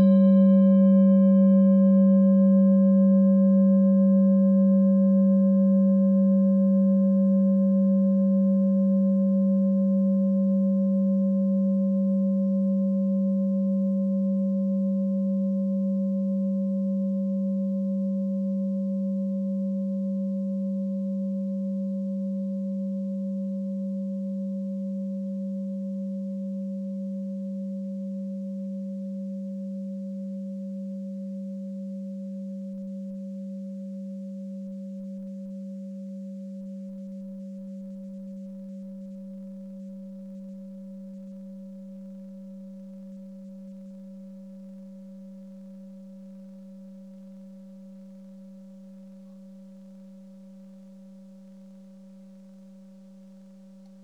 Tibet Klangschale Nr.12
Planetentonschale: Tageston
Sie ist neu und wurde gezielt nach altem 7-Metalle-Rezept in Handarbeit gezogen und gehämmert.
Hörprobe der Klangschale
Was den Klang anbelangt, sind handgearbeitete Klangschalen immer Einzelstücke.
(Ermittelt mit dem Filzklöppel oder Gummikernschlegel)
Wenn man die Frequenz des Mittleren Sonnentags 24mal oktaviert, hört man sie bei 194,18 Hz.
Auf unseren Tonleiter entspricht er etwa dem "G".
klangschale-tibet-12.wav